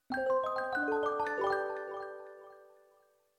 Old Music Box 4
bonus-sound film-production game-development intro magic music-box mystic mystical sound effect free sound royalty free Music